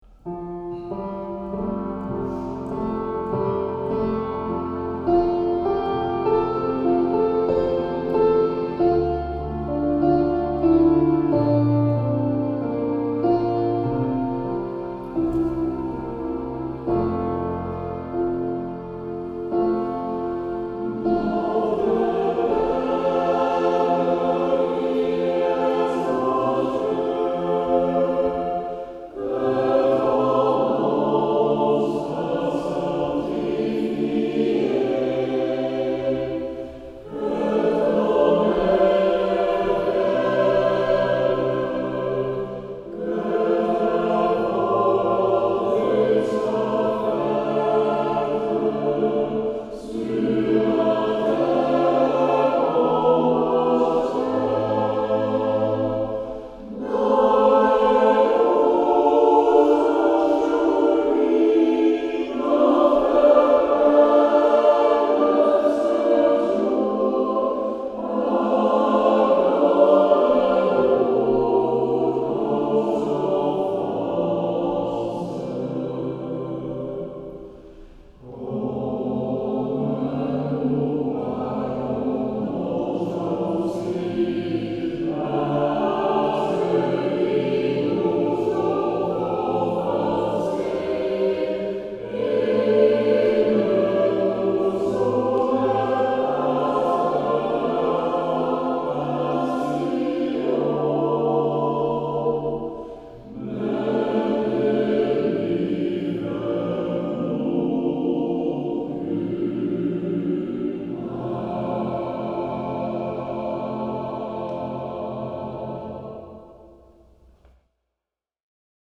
Er waren plm. 25 koorleden aanwezig in corona-opstelling.